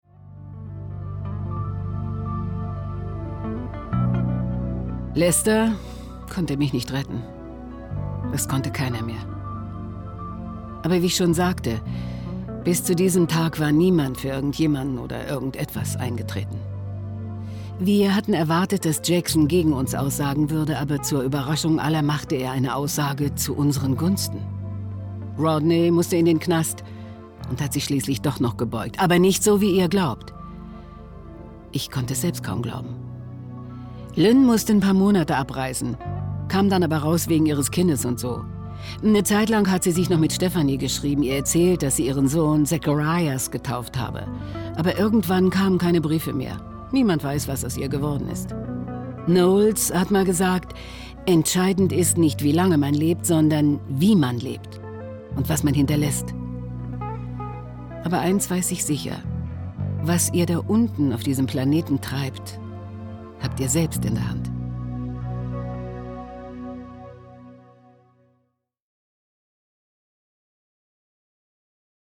dunkel, sonor, souverän
Mittel plus (35-65)
Lip-Sync (Synchron)